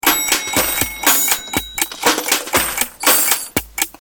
clock_tick.mp3